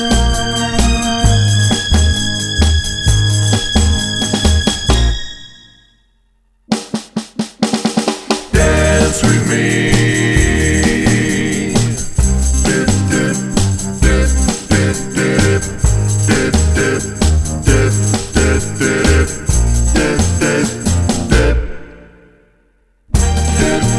Two Semitones Down Soul / Motown 2:33 Buy £1.50